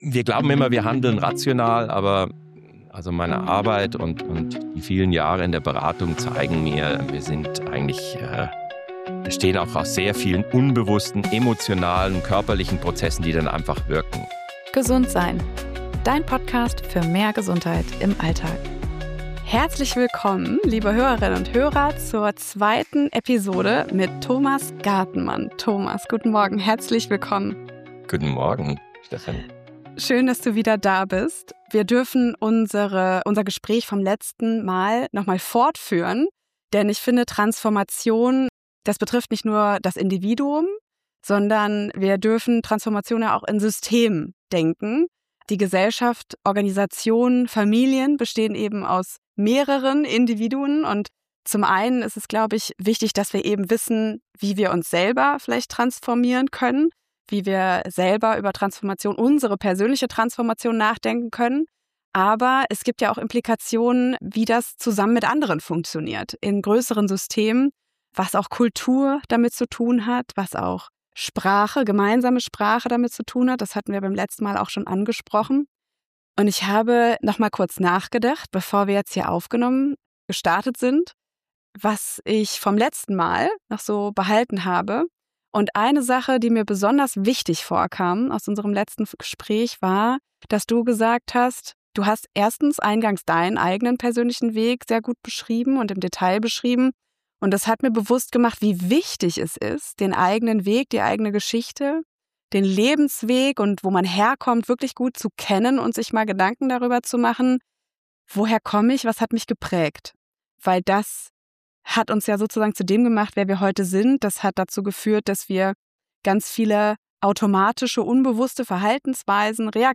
Warum scheitern so viele Veränderungsprozesse an der Oberfläche? Und welche Rolle spielt das Nervensystem dabei, auch in Führungsetagen? Ein Gespräch, das zeigt, wie tief Wandel gehen muss, damit er wirklich etwas bewegt.